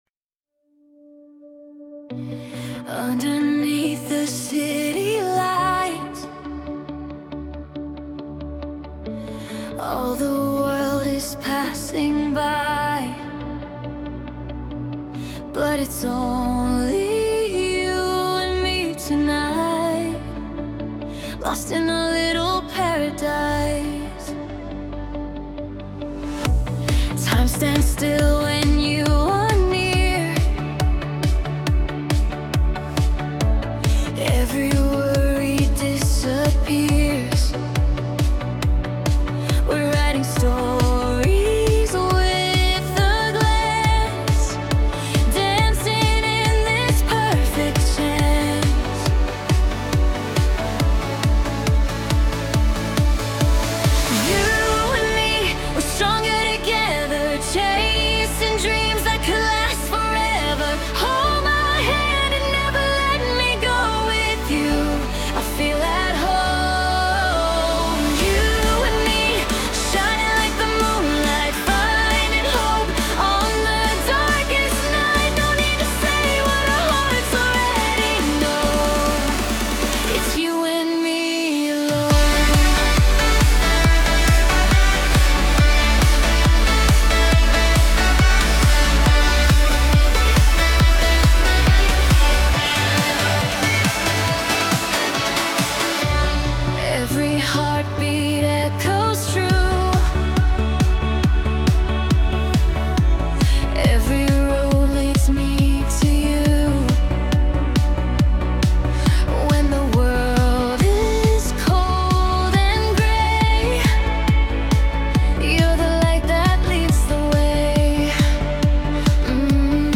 Трек размещён в разделе Зарубежная музыка / Инди.